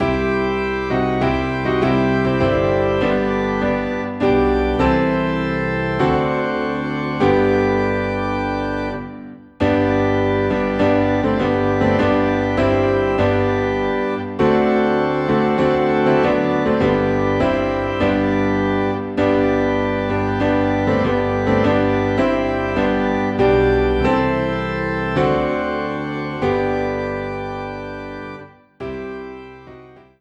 PIANO AND ORGAN DUET SERIES